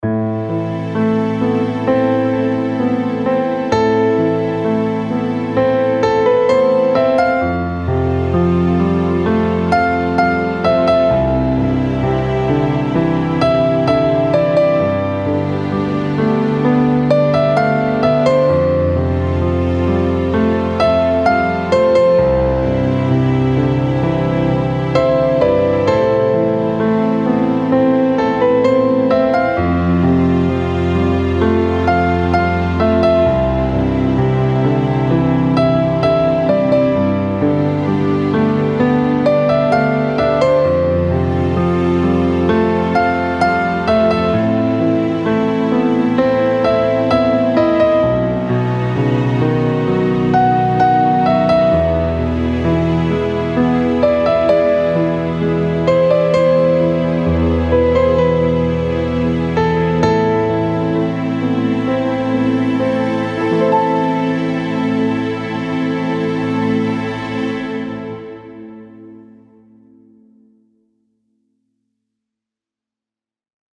Interlude
(Instrumental)